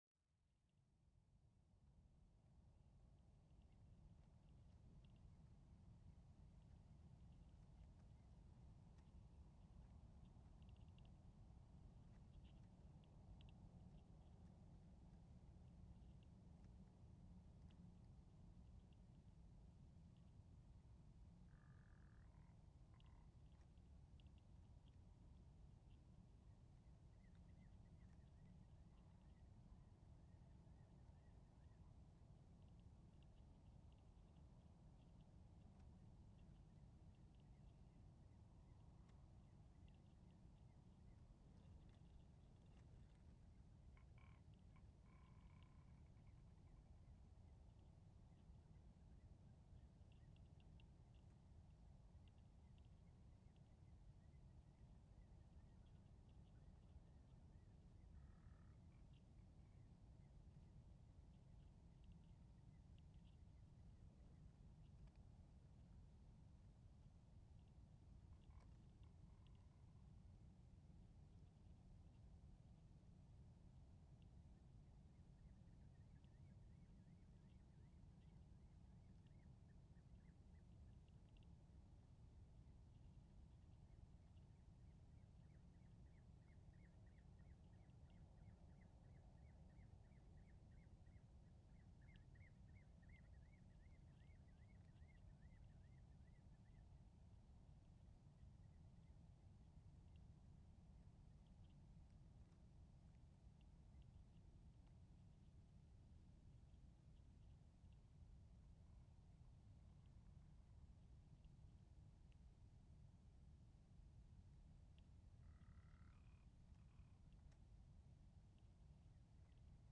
One year ago I was recording in Skaftafell national park and neighborhood in southeast of Iceland.
This recording is a recording of silence.
You will hear some birds and insects. With good headphones you will hear the rumble sound of heavy surf on the beach 20 to 30 km far away. BUT this recording is not completely quiet.
Two birds, a male and female Rock Ptarmigan, are coming very close, „talking“ loud into your ears (2:05).